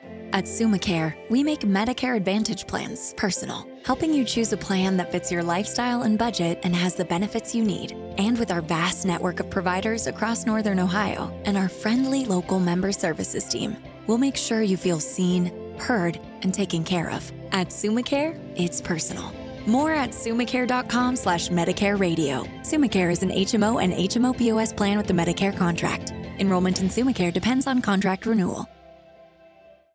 Brand Radio Ad